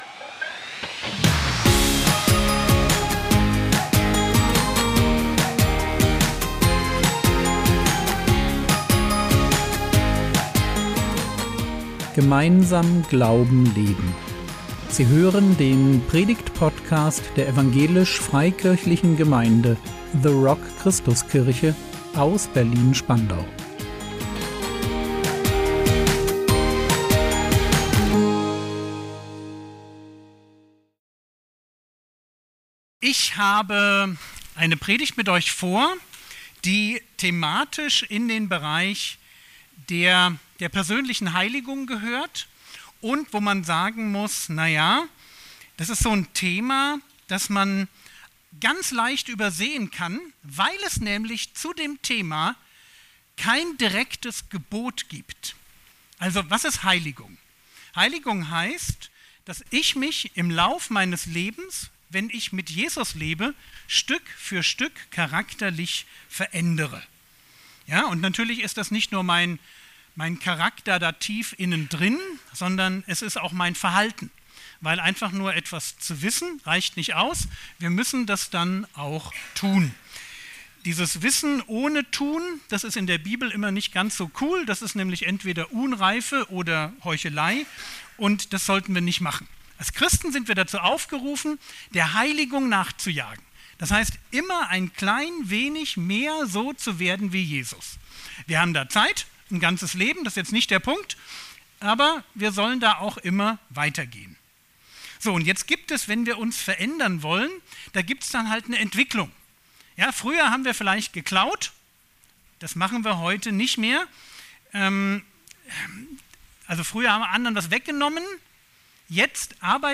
Gott stellt (gute) Fragen - Du auch? | 15.06.2025 ~ Predigt Podcast der EFG The Rock Christuskirche Berlin Podcast